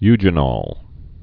(yjə-nôl, -nōl, -nŏl)